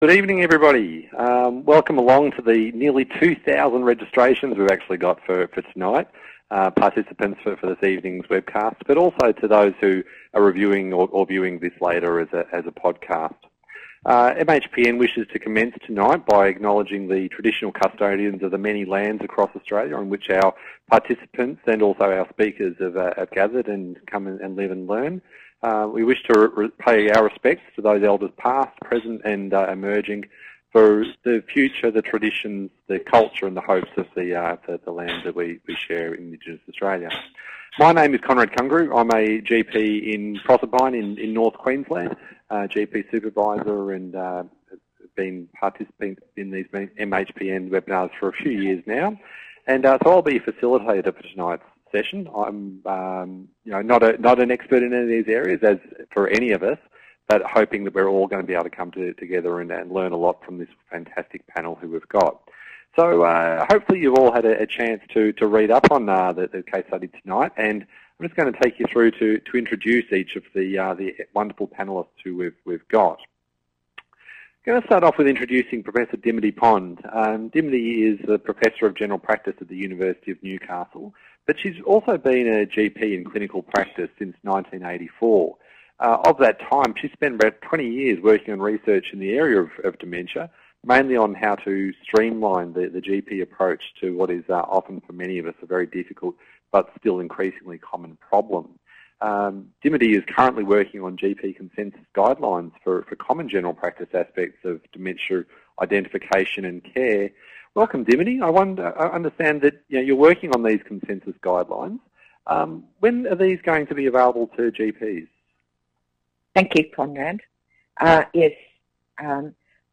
Join our interdisciplinary panel of experts to explore working collaboratively to support people with dementia and their families
Panellists Discussion